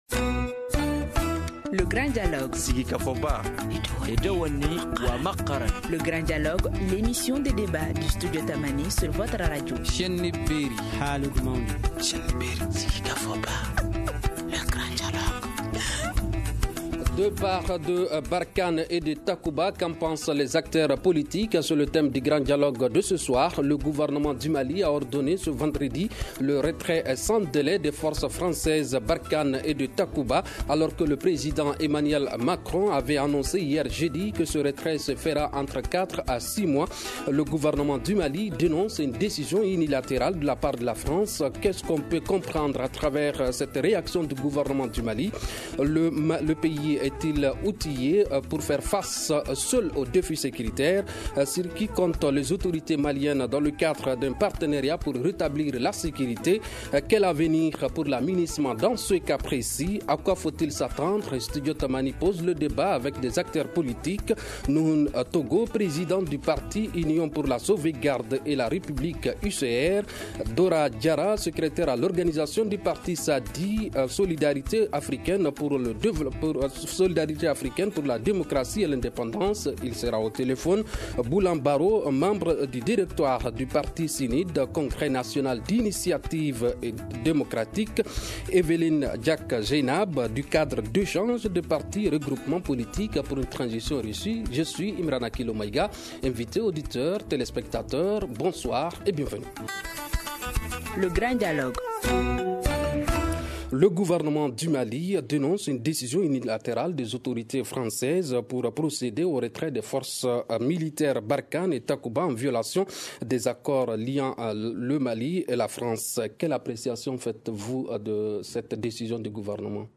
Studio Tamani ouvre le débat avec des acteurs politiques maliens